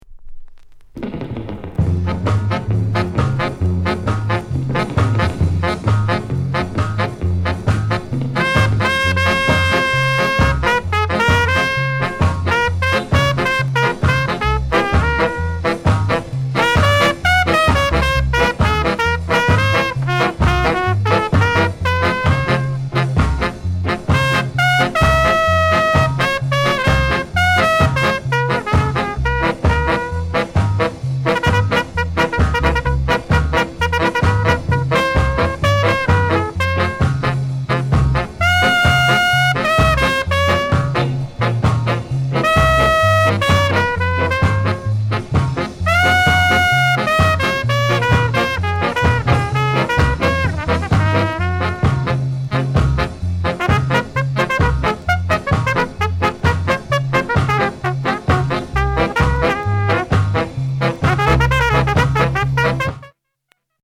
BIG SKA INST